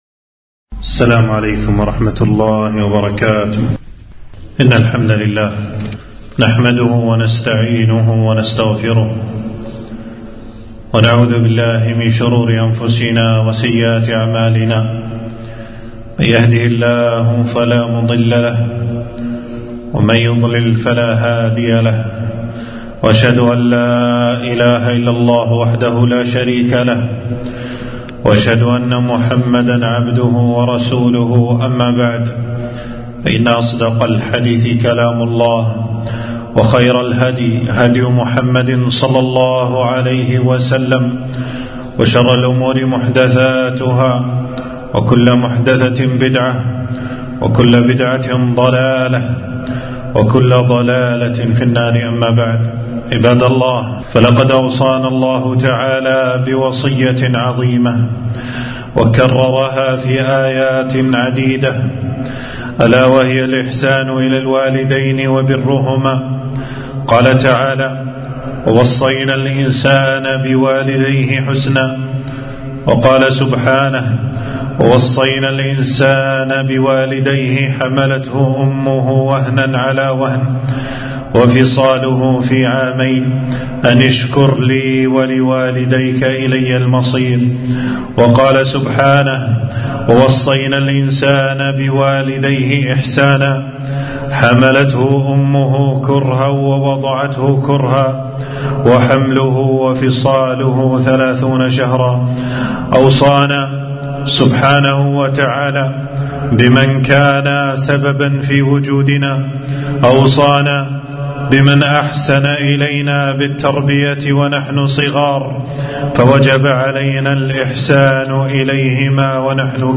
خطبة - بر الوالدين فضله وصوره